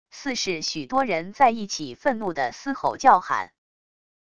似是许多人在一起愤怒的嘶吼叫喊wav音频